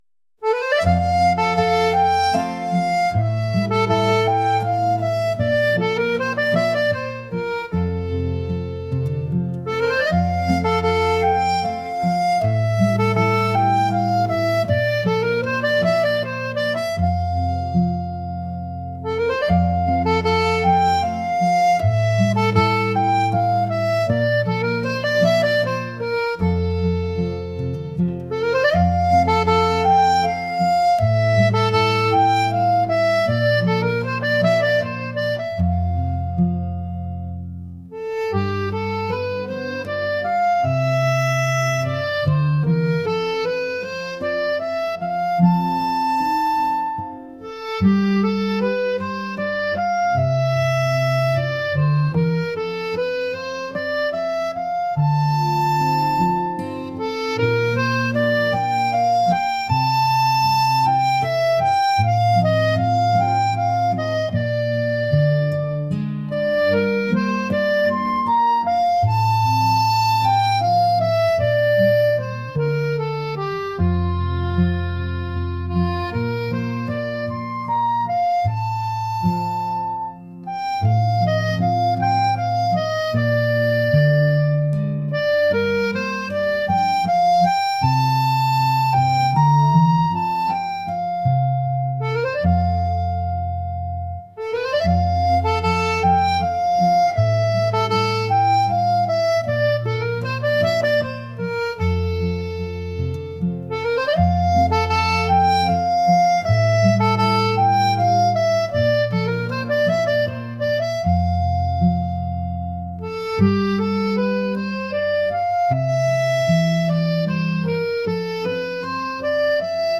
folk | traditional